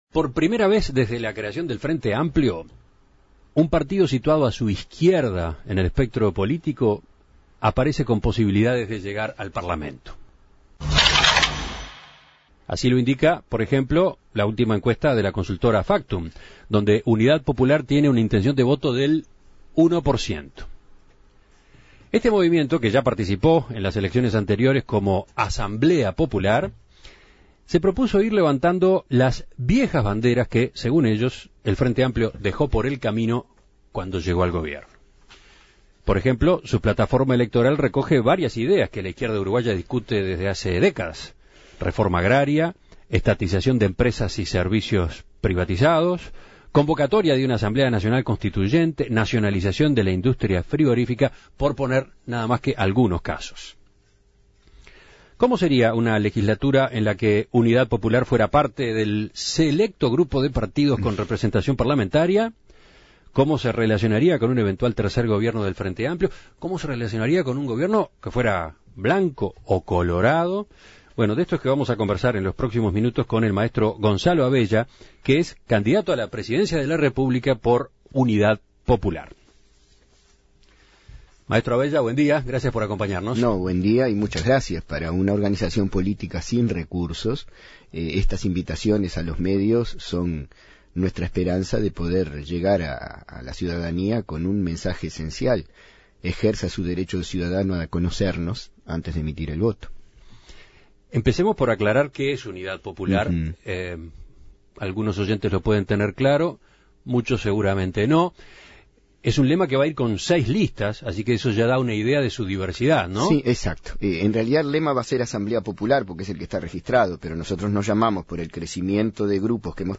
En entrevista con En Perspectiva